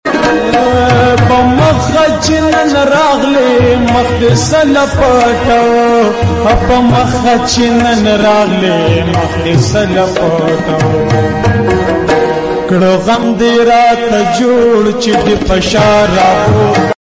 pashto song | pashto tapay